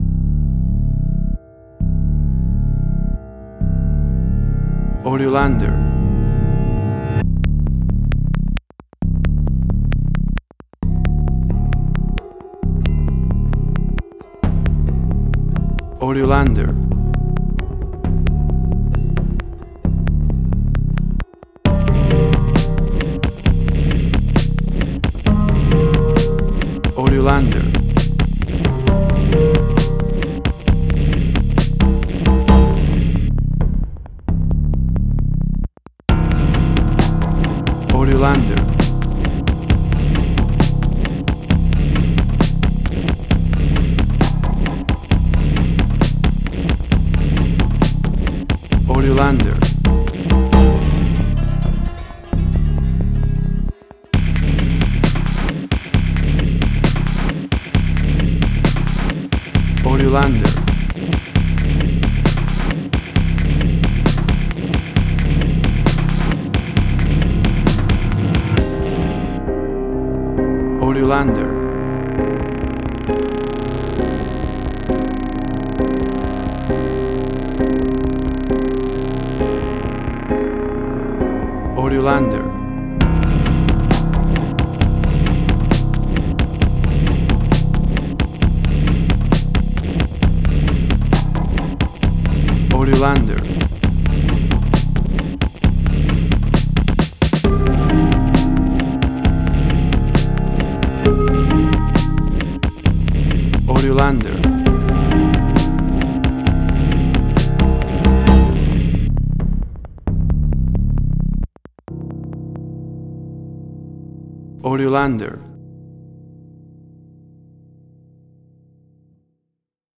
Suspense, Drama, Quirky, Emotional.
Tempo (BPM): 66